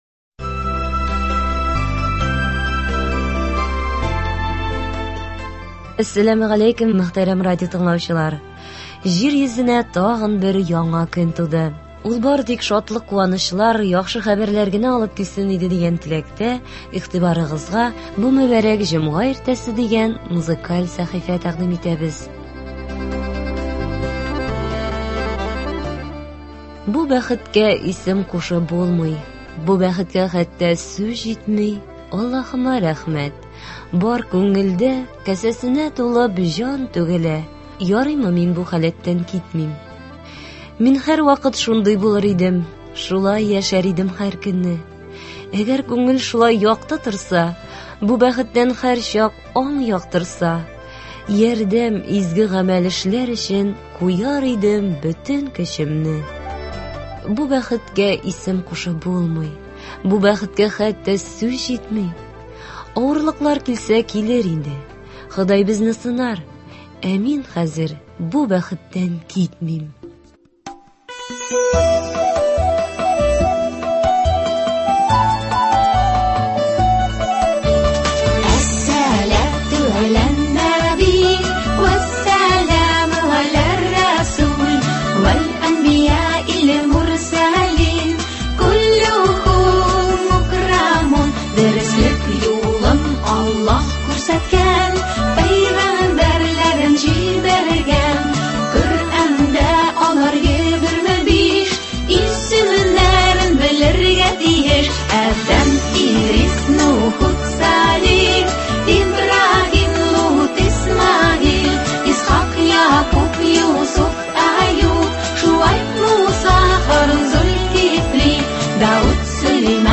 Музыкаль мизгелләр – һәр эш көнендә иртән безнең эфирда республикабыз композиторларының иң яхшы әсәрләре, халкыбызның яраткан җырлары яңгырый.